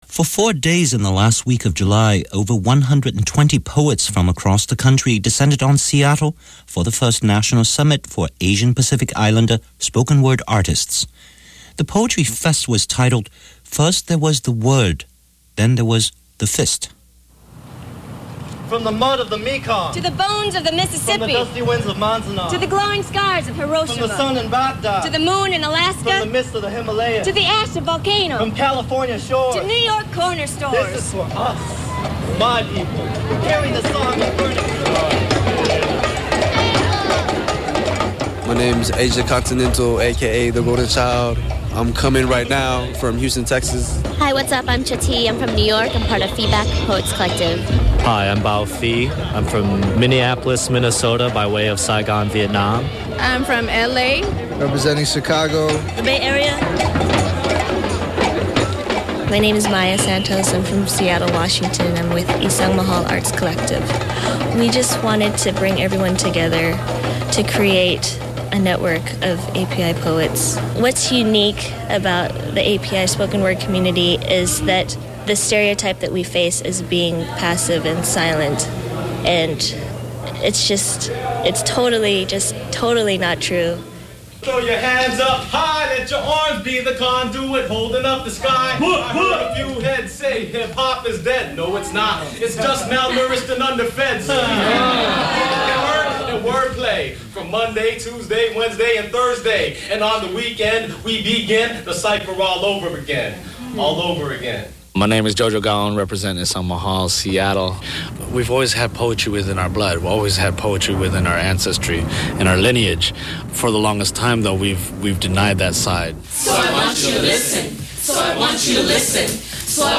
API-Spoken-Word-Summit-2001.mp3